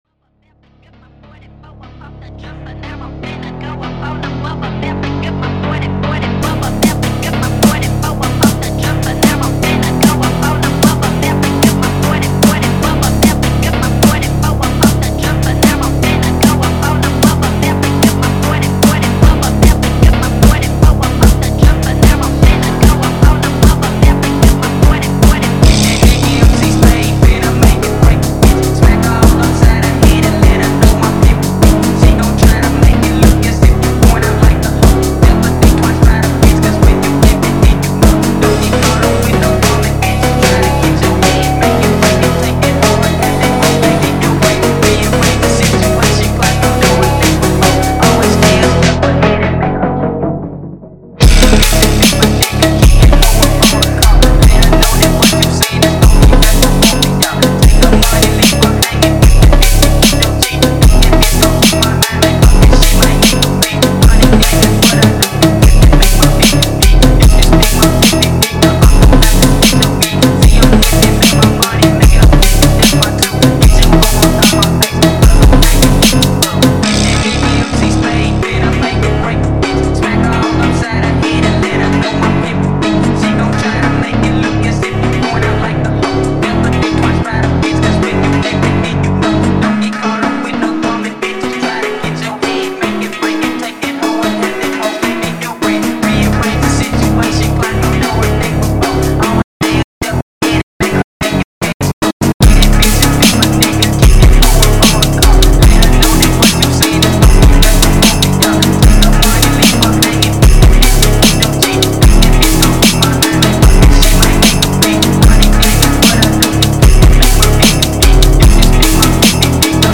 Фонк